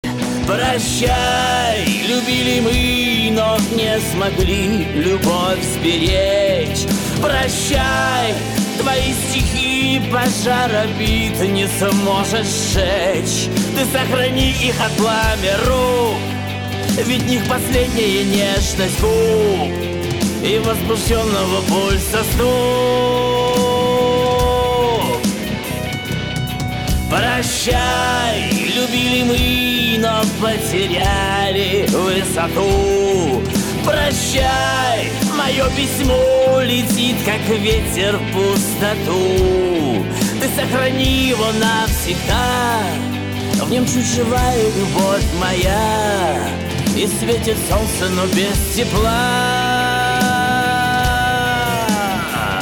• Качество: 320, Stereo
мужской вокал
грустные
русский шансон
печальные